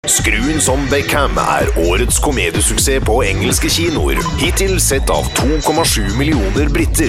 Professioneller norwegischer Sprecher für TV/Rundfunk/Industrie.
norwegischer Sprecher
Sprechprobe: Industrie (Muttersprache):
norwegian voice over